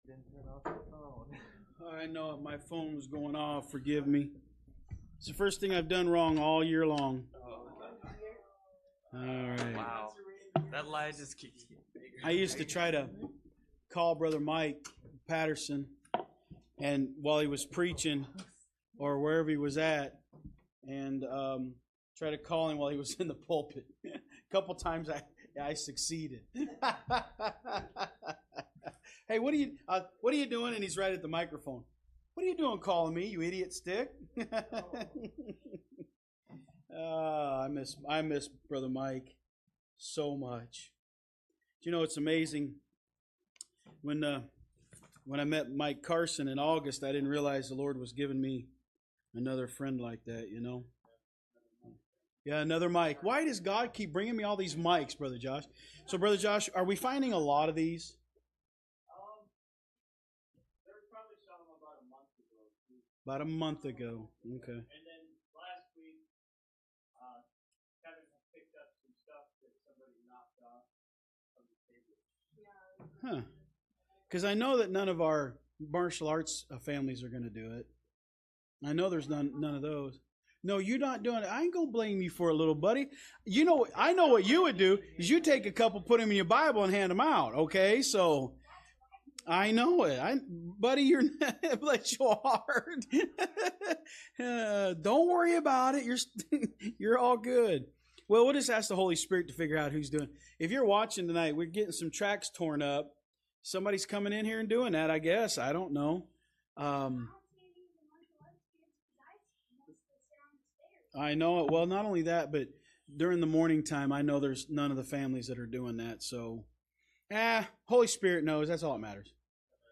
From Series: "Wednesday Service"